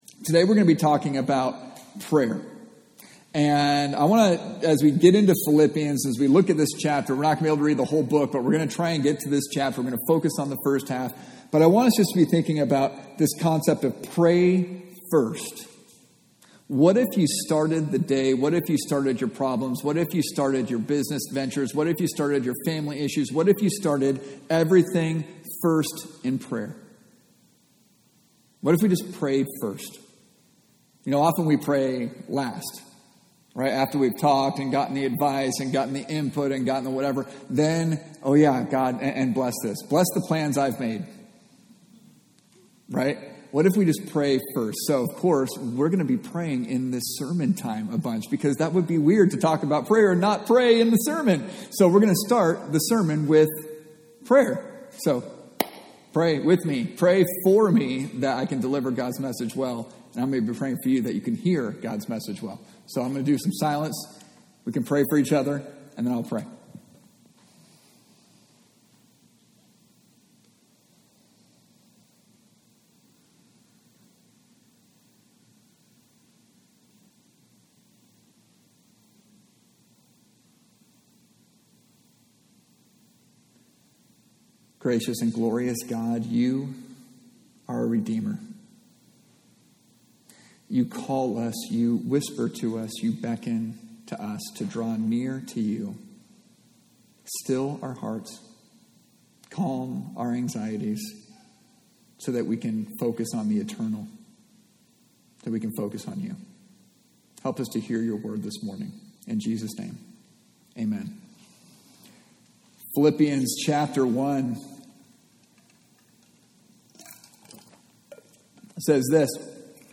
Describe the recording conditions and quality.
Passage: Philippians 1 Service Type: Sunday Morning